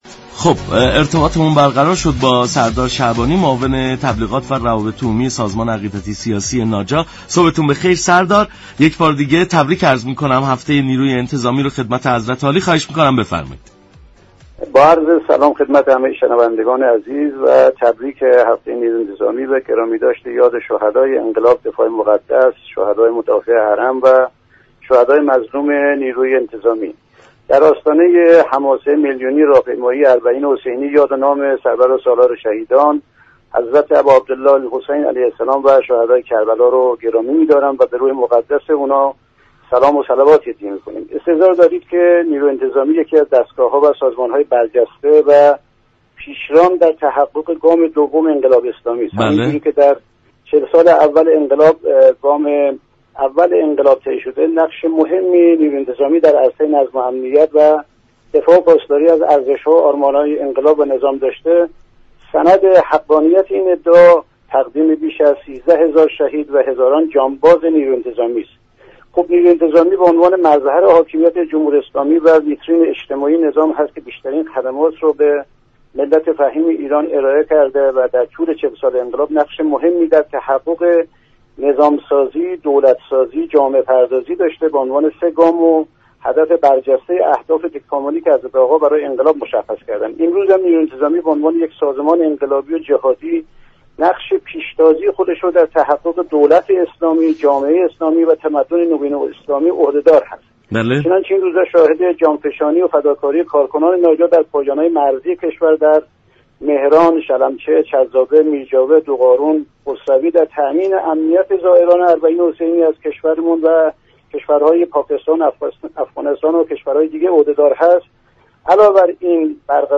به گزارش شبكه رادیویی ایران، سردار شعبانی معاون تبلیغات و روابط عمومی سازمان عقیدتی سیاسی ناجا در گفت و گو با برنامه «سلام صبح بخیر» ضمن تبریك هفته نیروی انتظامی گفت: نیروی انتظامی یكی از نهادها و دستگاه های برجسته و پیشران در جهت تحقق گام دوم انقلاب اسلامی، نقش مهمی را در برقراری نظم و امنیت كشور ایفا می كند.